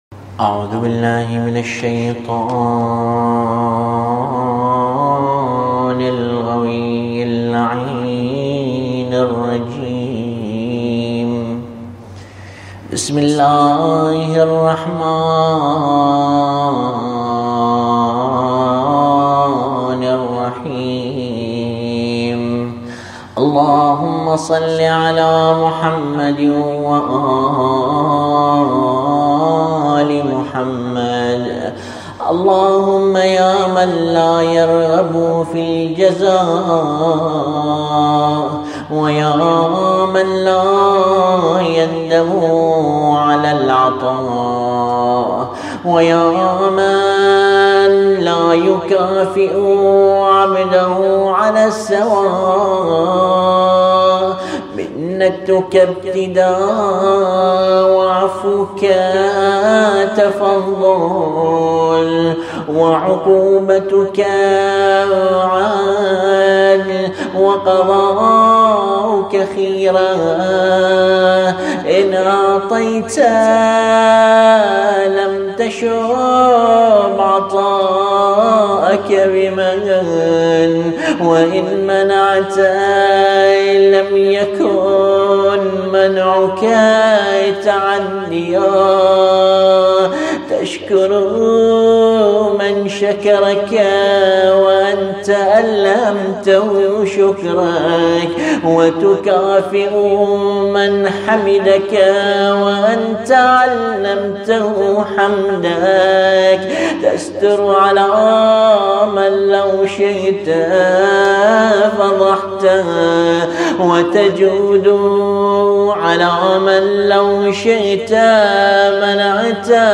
It is a supplication bidding adieu to the blessed month of fasting and self-reformation, a month that is beloved by the friends of Allah. As such, the supplication is read with the sorrow and sadness, just as one would grieve when saying goodbye to an intimate companion or a near relative leaving for a year-long journey.